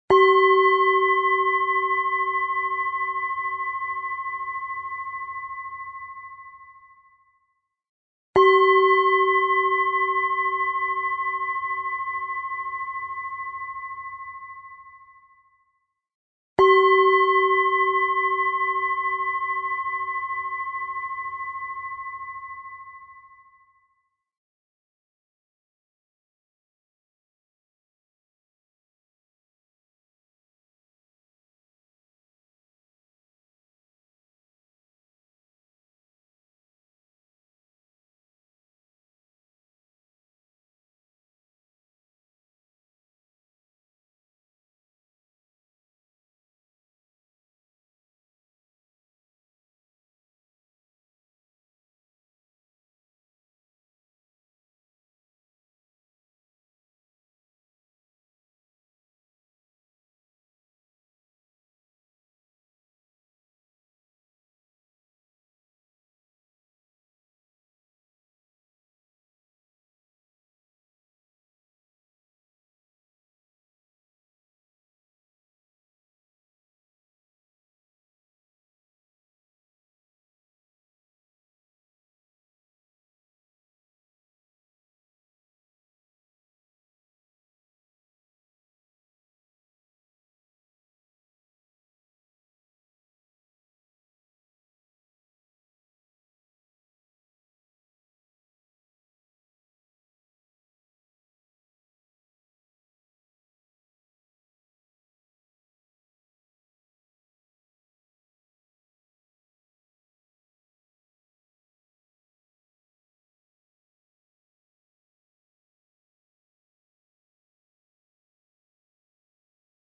ademruimte-8minutenstilte.mp3